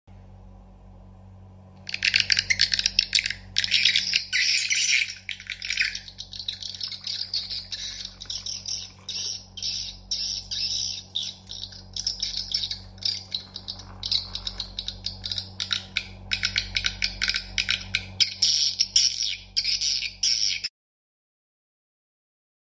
Audax Horn Tweeter AX 2R sound effects free download